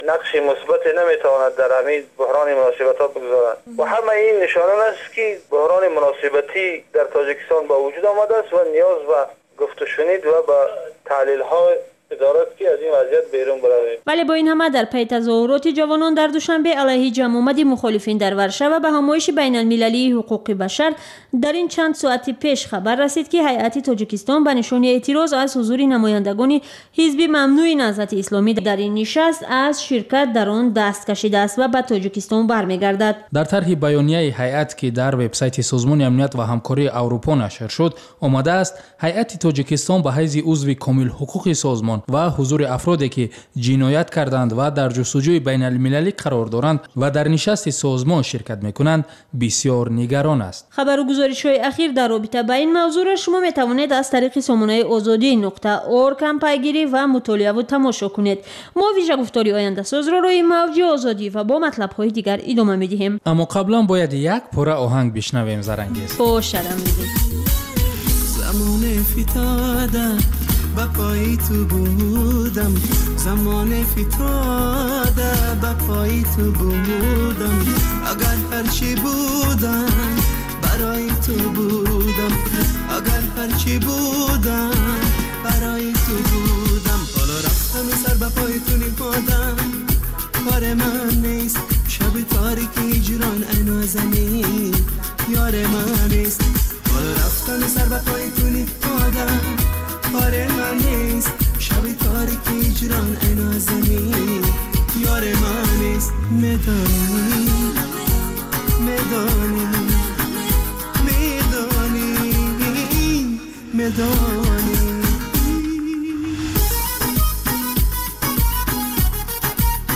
Хабарҳои иқтисодии Тоҷикистон, минтақа ва ҷаҳон. Баррасии фарояндаҳо ва падидаҳои муҳим дар гуфтугӯ бо коршиносон.